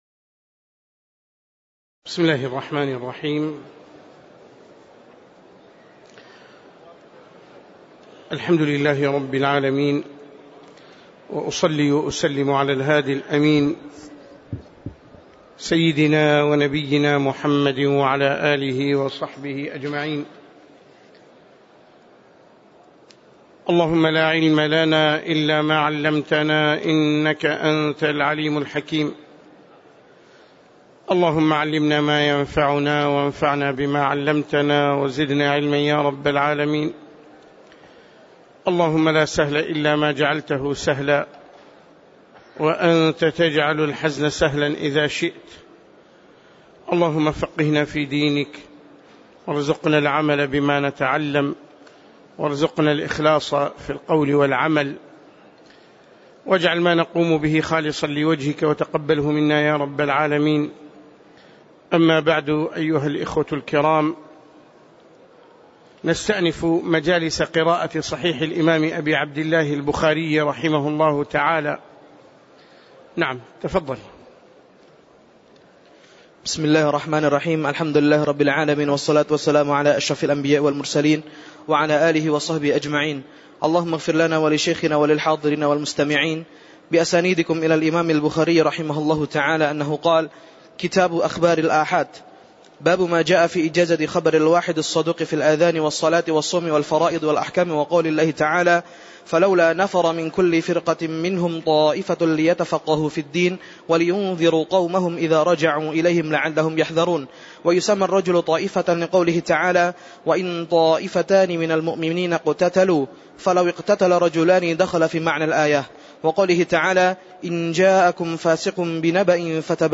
تاريخ النشر ٤ جمادى الأولى ١٤٣٩ هـ المكان: المسجد النبوي الشيخ